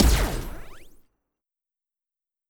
ray_gun2.wav